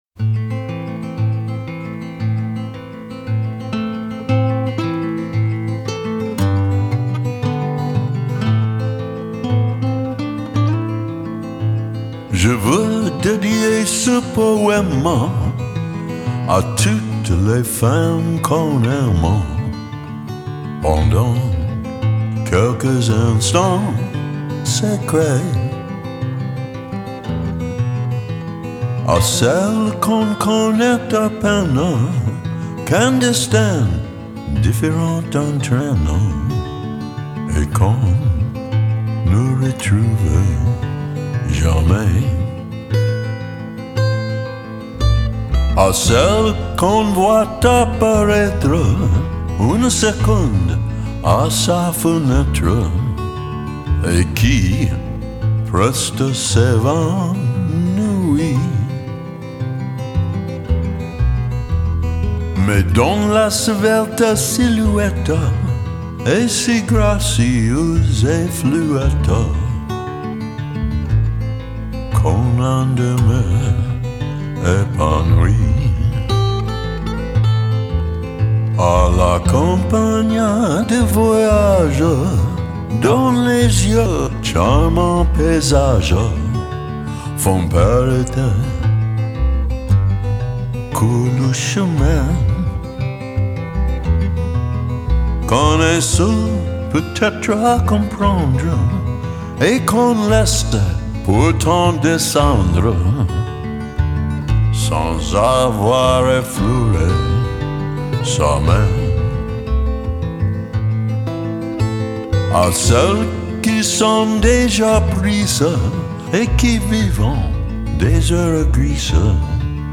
Genre: Vintage Lounge, Chanson, Vocal Jazz